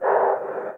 breath1gas.ogg